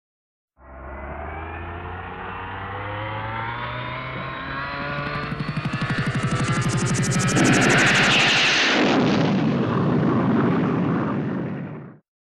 BSG FX - Viper - Launches from launch tube
BSG_FX_-_Viper_-_Launches_from_Launch_Tube.wav